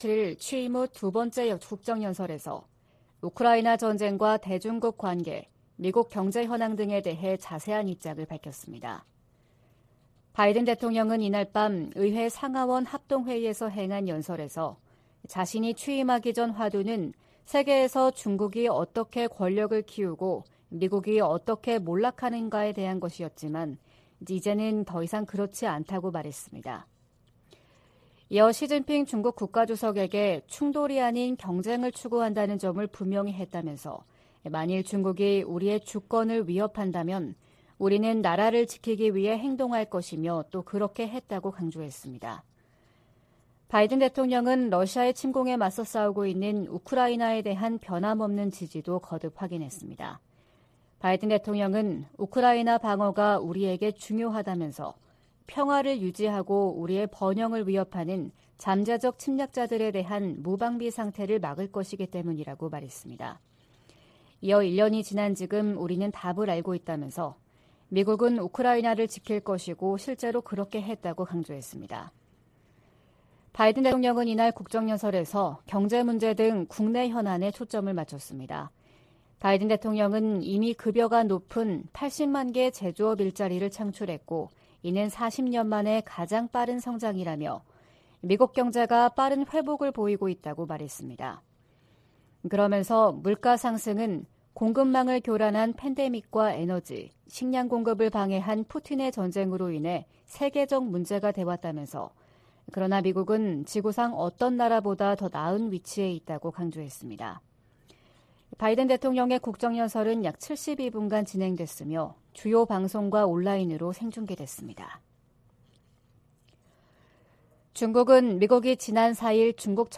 VOA 한국어 '출발 뉴스 쇼', 2023년 2월 9일 방송입니다. 조 바이든 미국 대통령은 2일 국정연설을 통해 중국이 미국의 주권을 위협한다면 ‘우리는 나라를 보호하기 위해 행동할 것’이라고 말했습니다. 유엔이 국제적 긴장을 고조시키는 북한의 핵 개발과 미사일 발사에 우려한다는 입장을 거듭 확인했습니다.